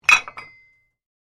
Звук штанги в жиме лежа при взятии в руки